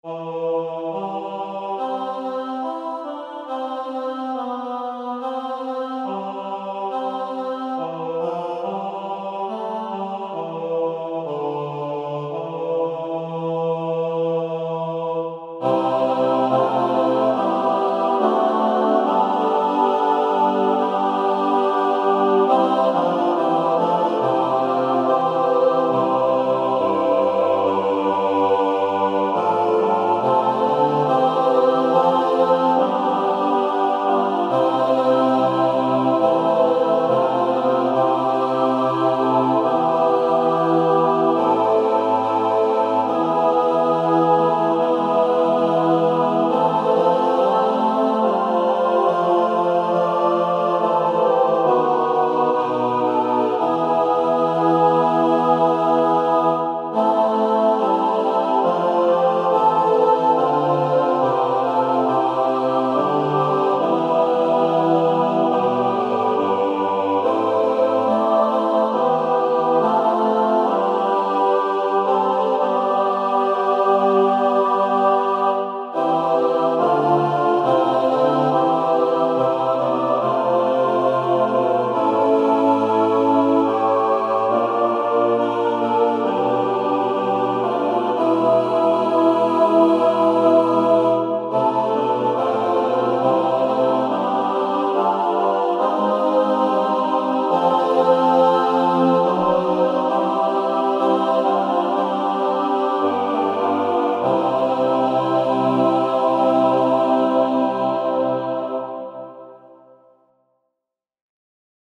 Title: Ave verum Composer: Louis Di Rocco Lyricist: Number of voices: 4vv Voicing: SATB Genre: Sacred, Motet
Language: Latin Instruments: A cappella